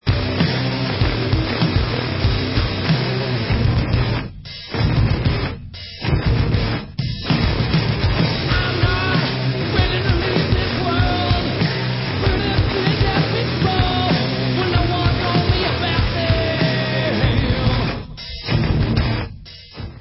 sledovat novinky v oddělení Rock/Alternative Metal